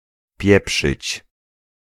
Ääntäminen
IPA: [ˈpʲɛpʂɨt͡ɕ]